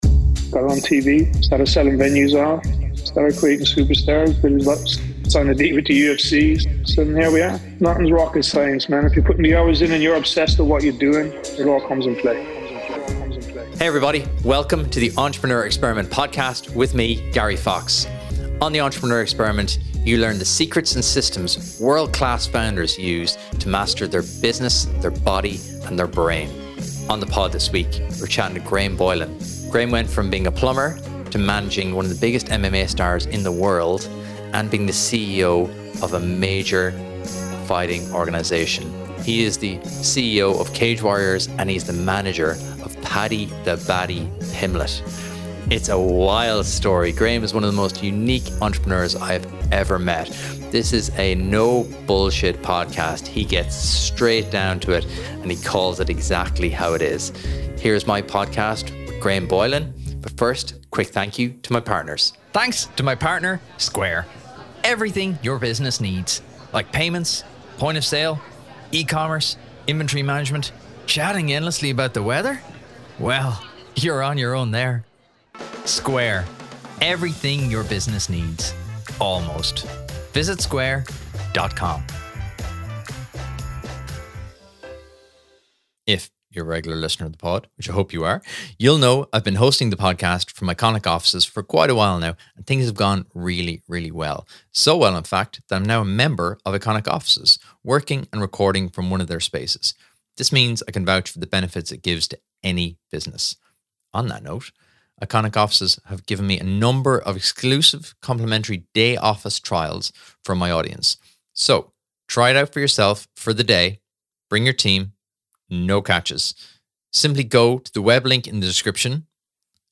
In his most detailed and in-depth interview ever recorded, he reveals his story & never heard before secrets of the MMA world.